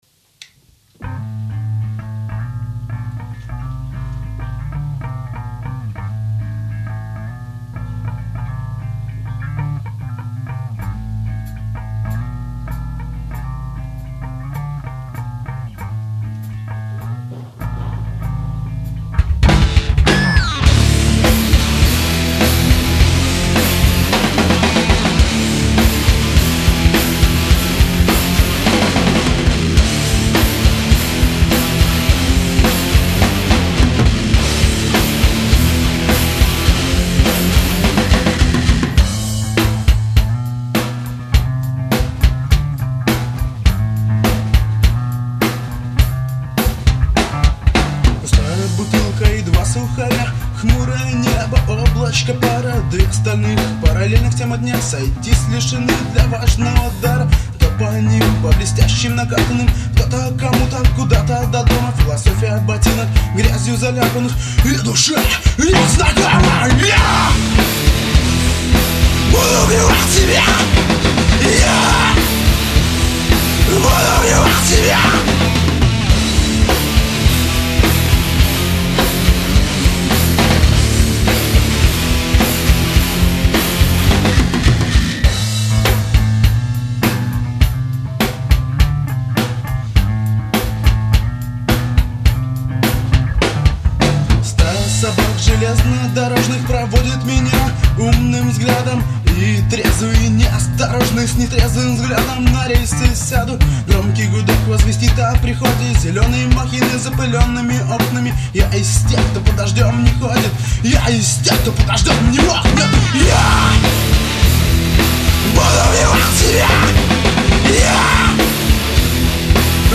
â êîíòåíò íå âòûêàë. âñòóïëåíèå çàòÿíóòî, âîêàë õèëåíüêèé...
çàïèñàíî íåäóðíî, à ìóçûêà - êàêàøêè ))